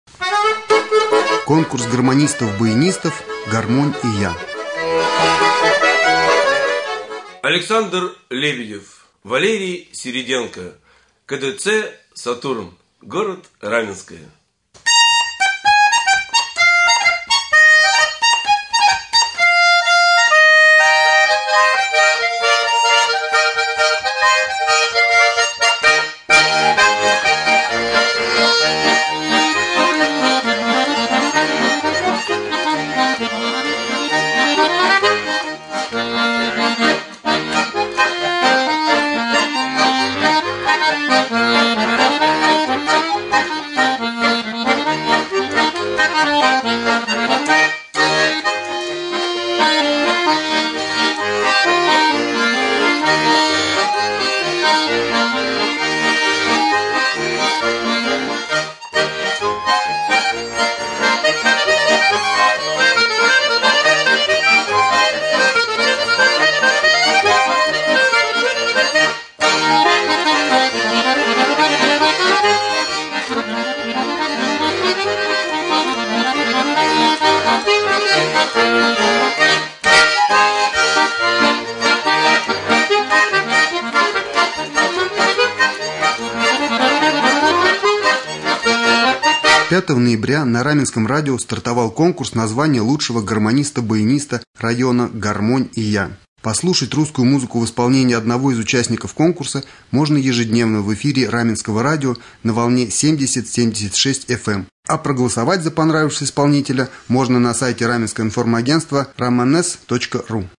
3.Гармонисты.mp3